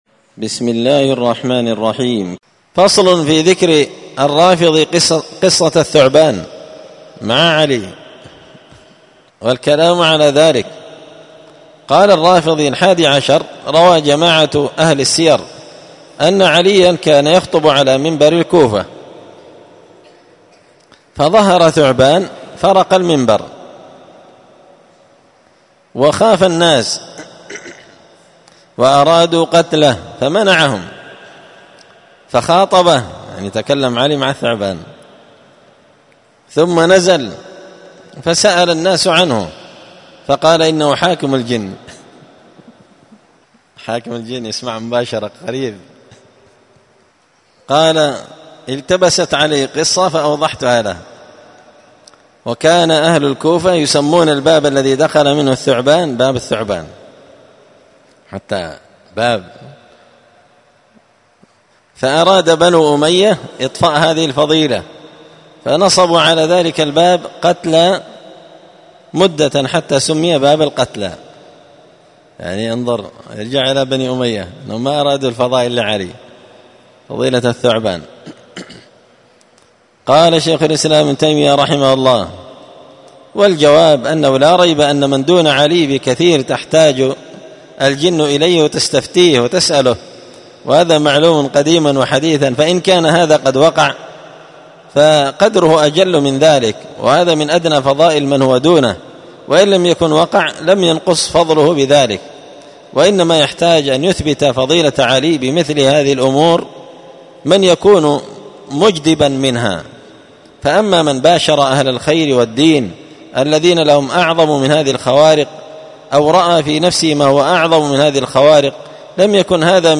الدرس الحادي والعشرون بعد المائتين (221) فصل في ذكر الرافضي قصة الثعبان مع علي والكلام عليه